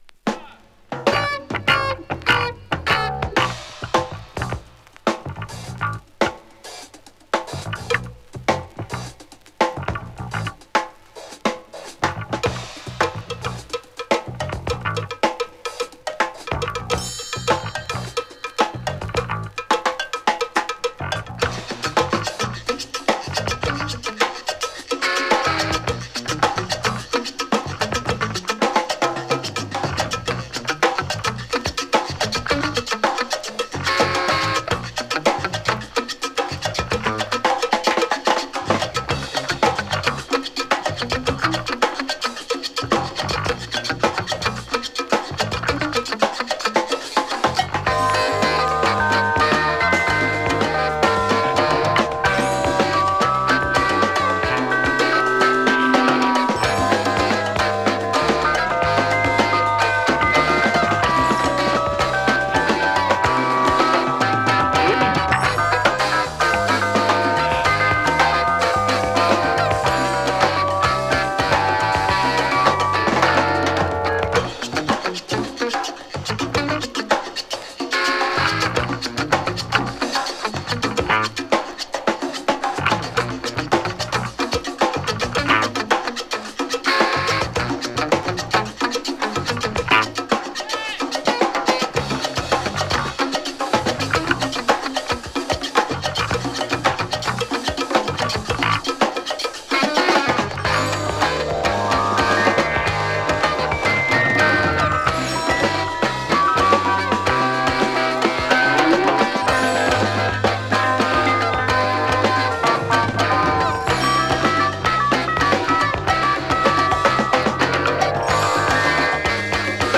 > JAZZ FUNK/RARE GROOVE